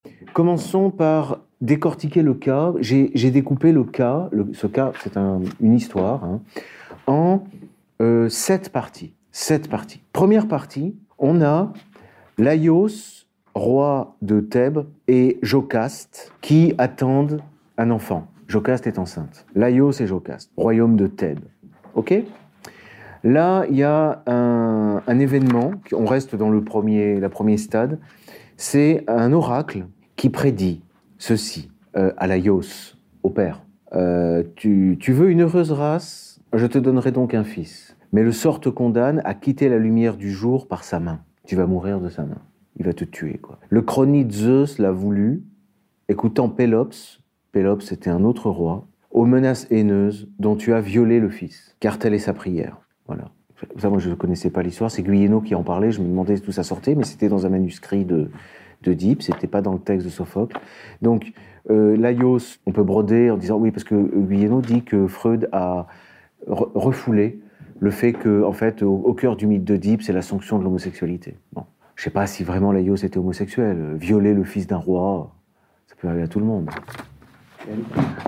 Le séminaire « le mythe d’Œdipe » dure une heure, c’est le live d’un cours de droit que j’ai délivré dans le cadre des Formations d’Egalité et Réconciliation.